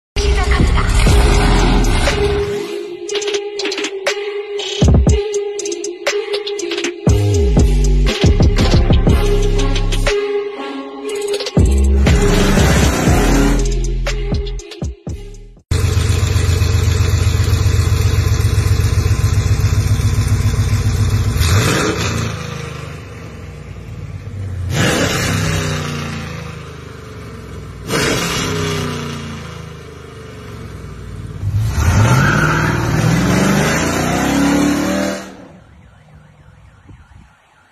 Hit the switch for that wide-open roar and pavement-pounding power when you want to go wild. Or keep things civilized with the muffled setting for a tame daily cruise. That raw clip at the end is proof - this Screamer system brings the thunder when unleashed.